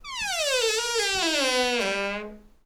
door_creak_long_02.wav